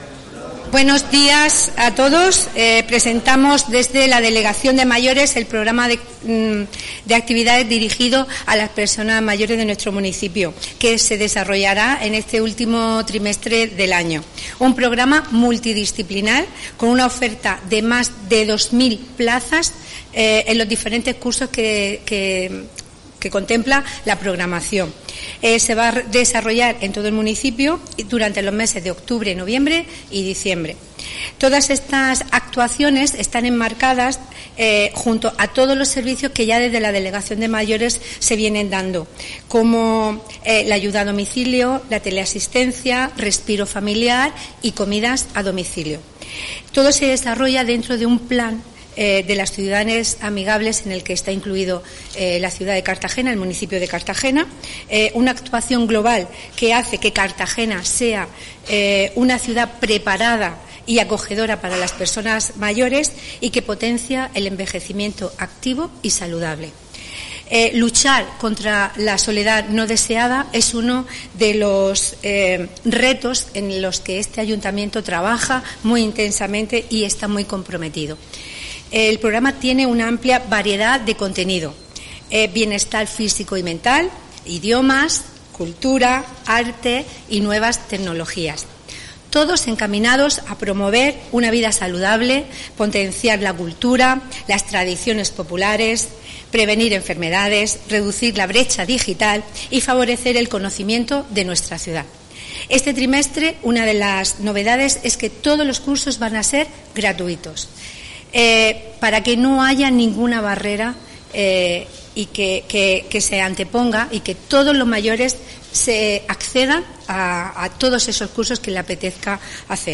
Audio: Presentación del Programa de Actividades para Mayores (MP3 - 7,45 MB)